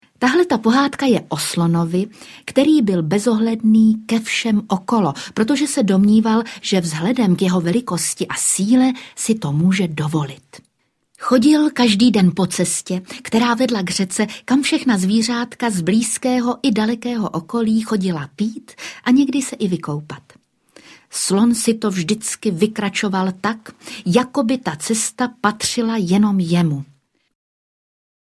Pohádky z pastelky audiokniha
Ukázka z knihy